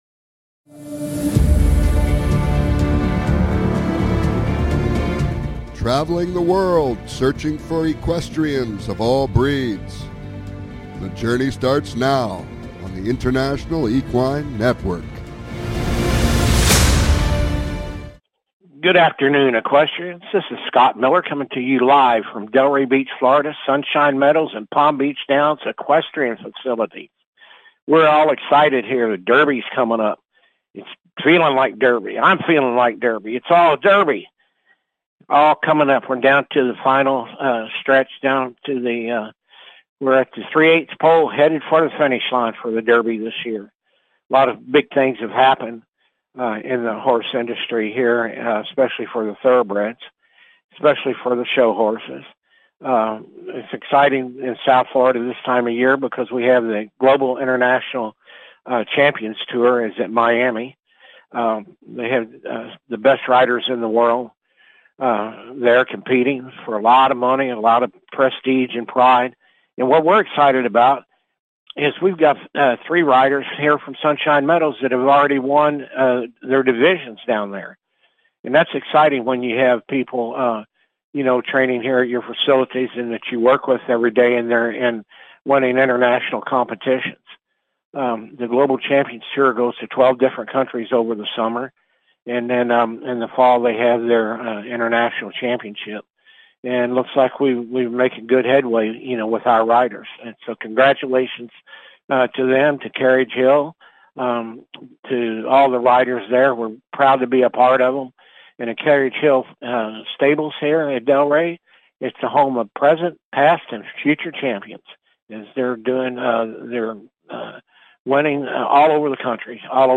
Talk Show Episode
Calls-ins are encouraged!